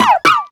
Cri de Rocabot dans Pokémon Soleil et Lune.